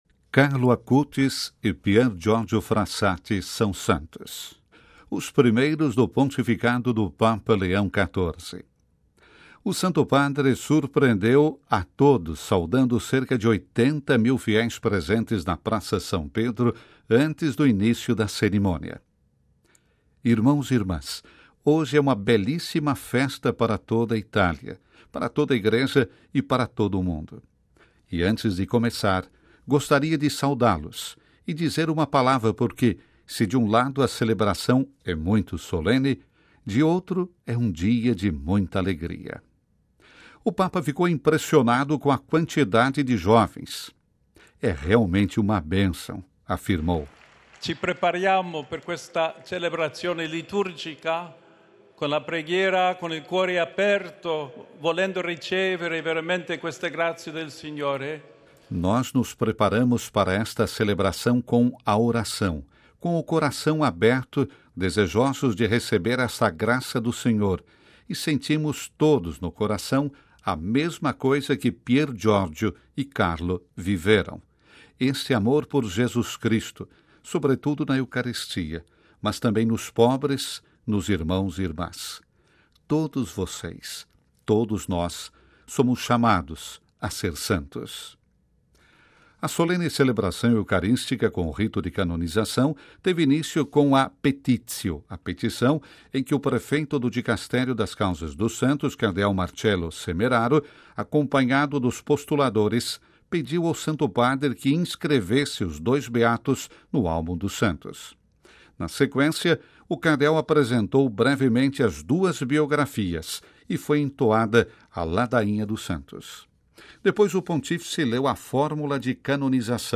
Ouça a reportagem completa com a voz do Papa Leão